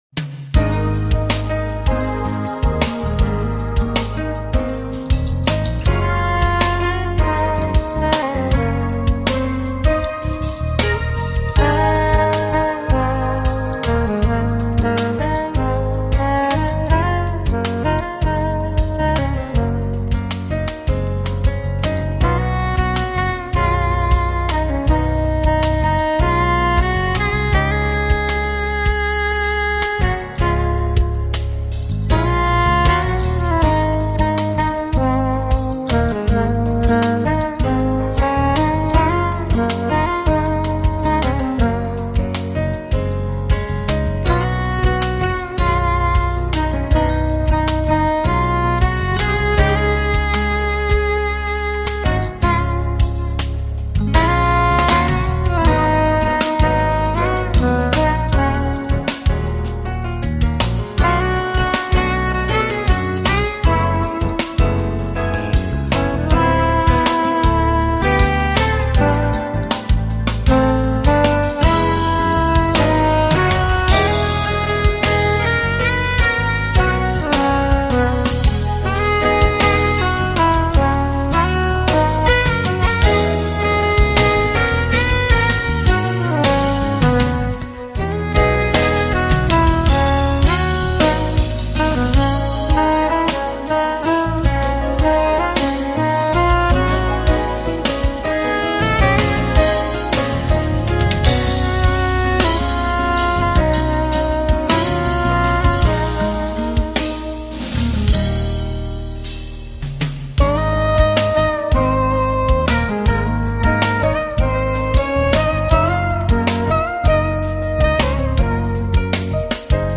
音乐风格：Smooth Jazz